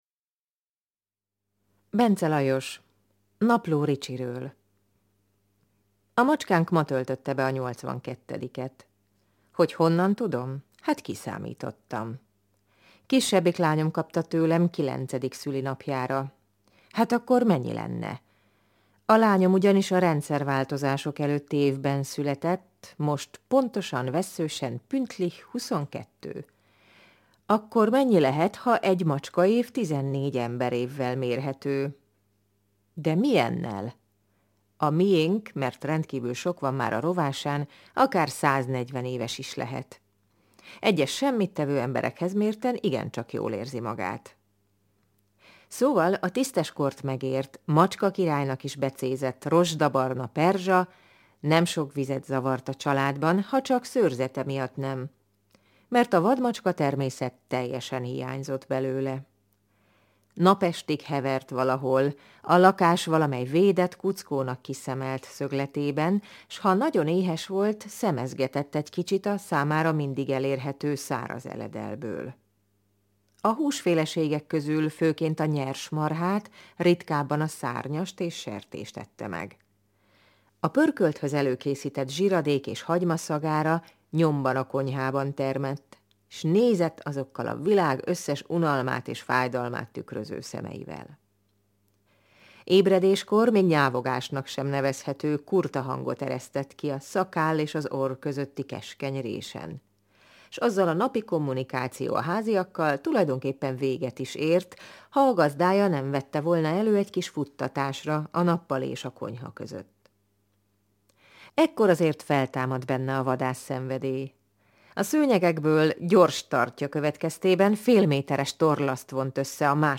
Hazai szerzőket olvasva